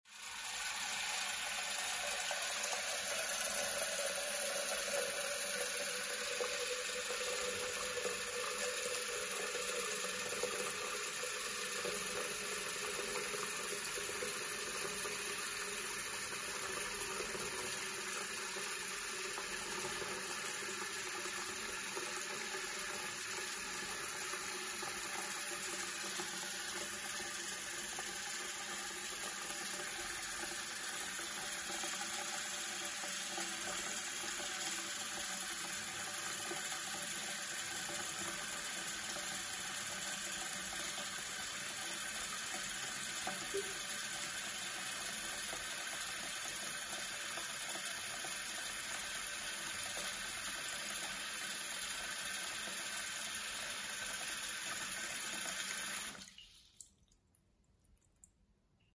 Звуки воды из крана
На этой странице собраны натуральные звуки воды из крана: от мягкого потока до отдельных капель.